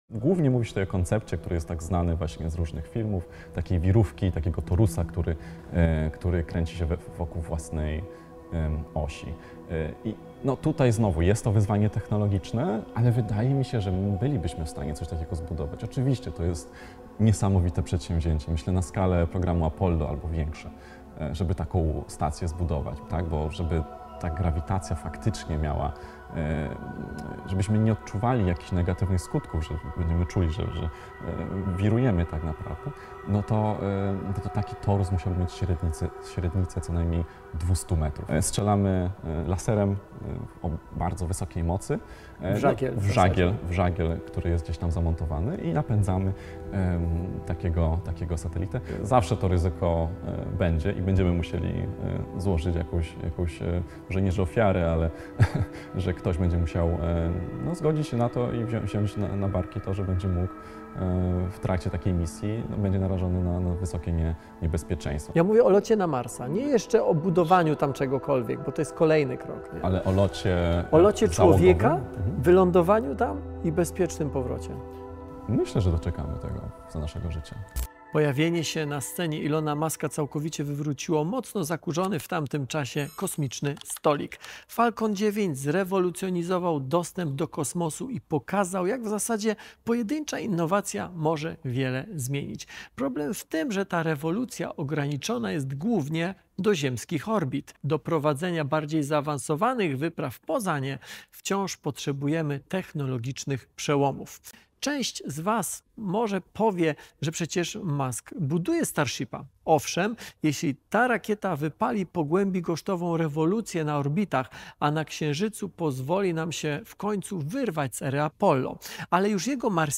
Czy lot na Marsa to science fiction, czy realna przyszłość? W tym fascynującym wywiadzie zagłębiamy się w sekrety kosmicznych podróży, analizując największe wyzwania i potencjalne przełomy technologiczne! Dowiedz się, dlaczego dzisiejsze rakiety chemiczne to za mało na podbój Czerwonej Planety i jakie problemy czekają na astronautów podczas wielomiesięcznej podróży.